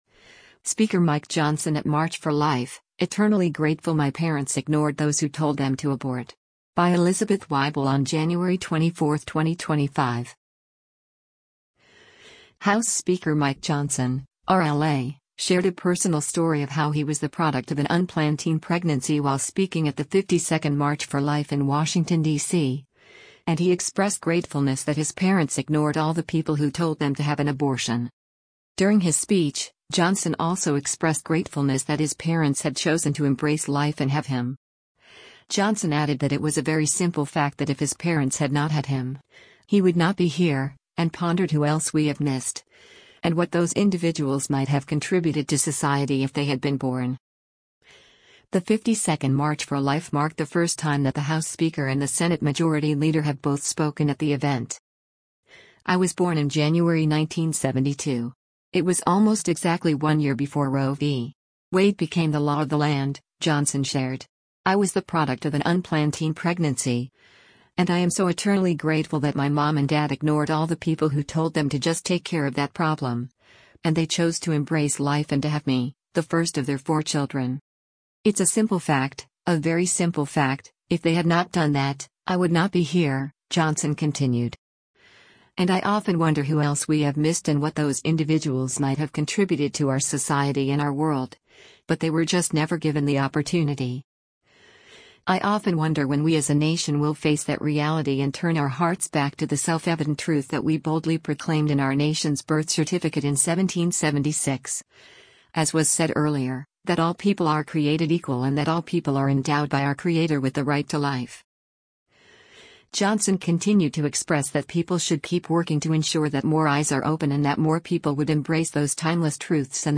House Speaker Mike Johnson (R-LA) shared a personal story of how he was the “product of an unplanned teen pregnancy” while speaking at the 52nd March for Life in Washington, DC, and he expressed gratefulness that his parents “ignored all the people who told them” to have an abortion.